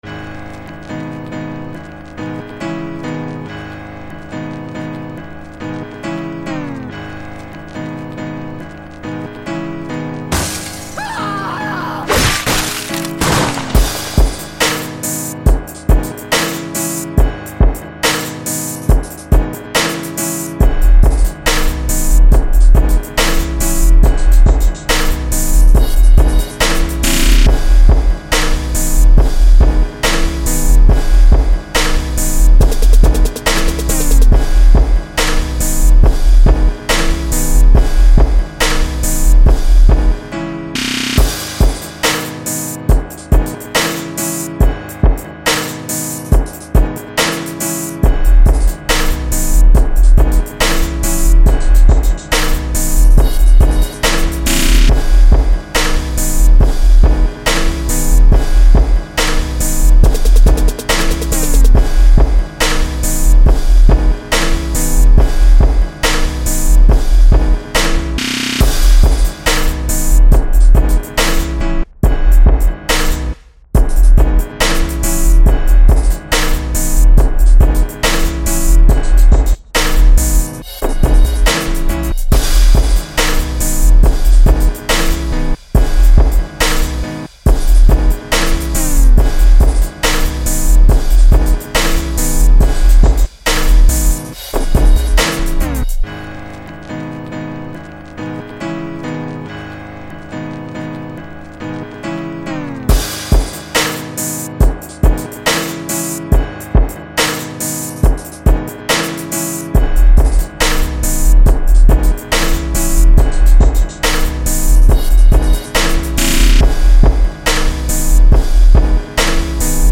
Premium Fire Rap Hip-hop Instrumentals